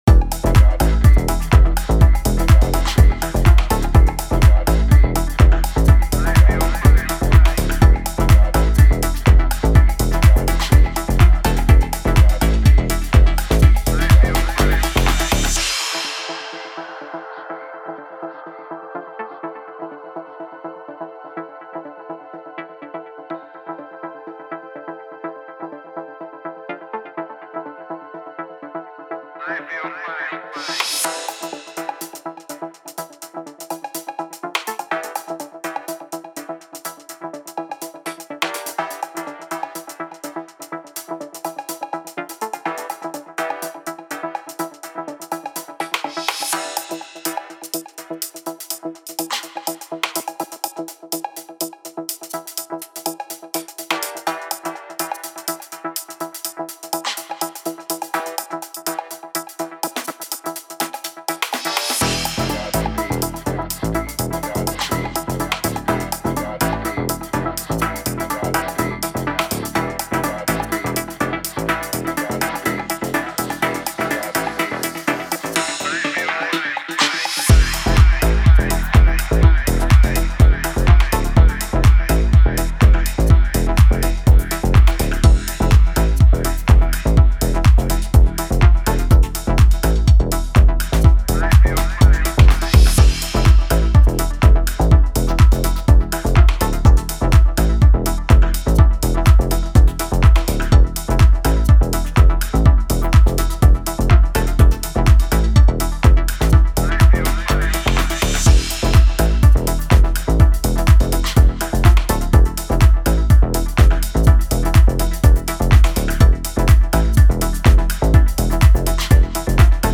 Tech House - Сведение